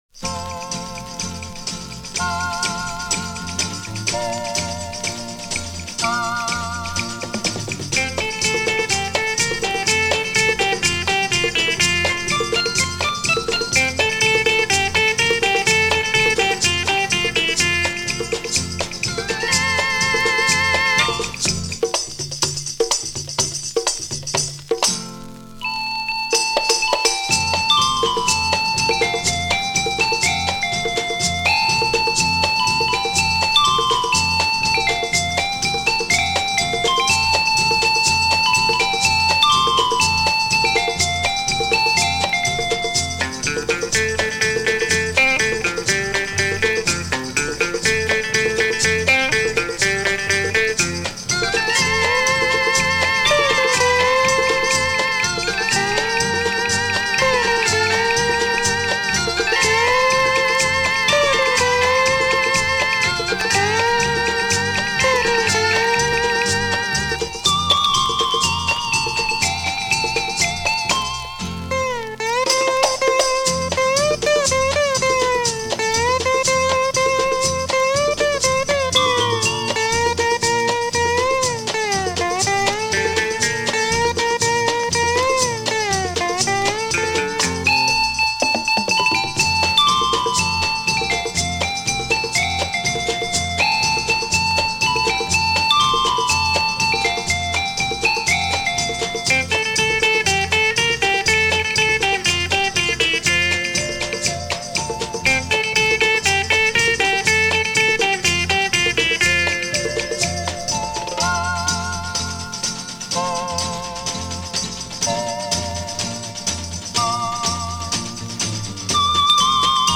Lollywood Steel Guitar?